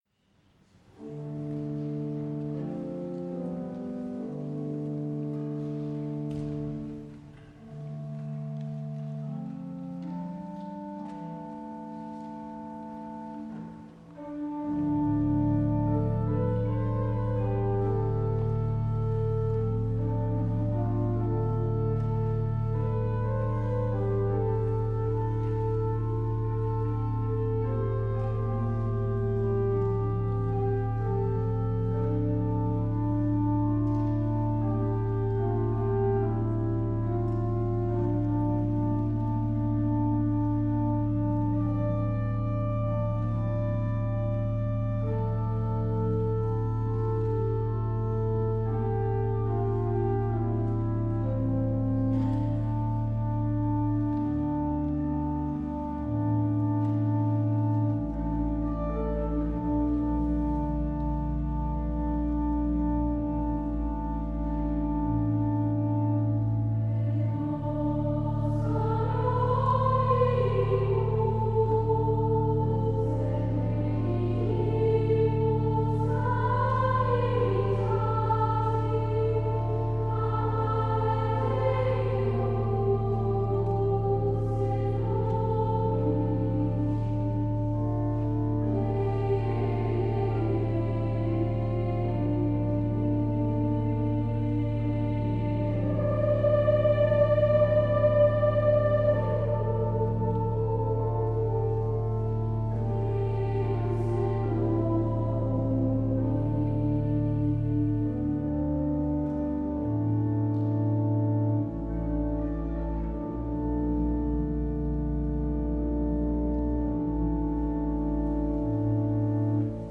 Chor-Einzelausgabe SSA + Orgel Serie
Live-Mitschnitt